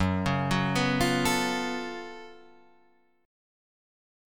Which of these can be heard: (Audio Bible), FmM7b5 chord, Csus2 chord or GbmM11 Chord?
GbmM11 Chord